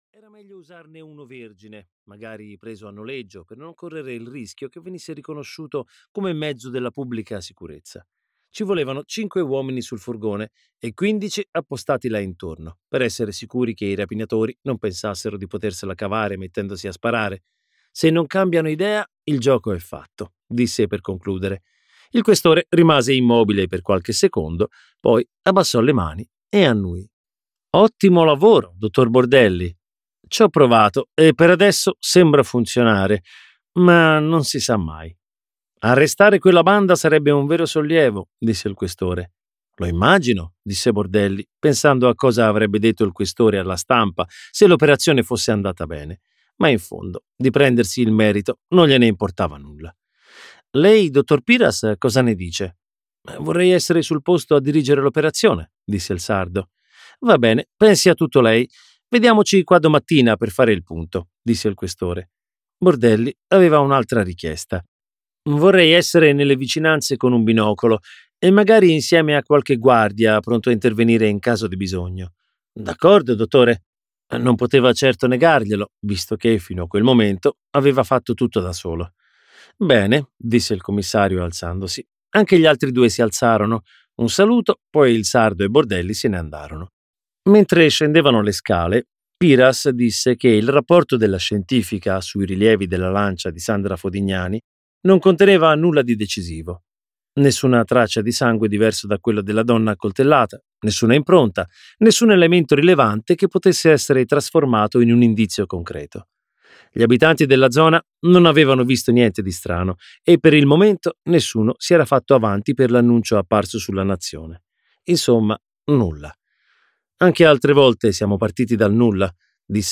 "Notti nere" di Marco Vichi - Audiolibro digitale - AUDIOLIBRI LIQUIDI - Il Libraio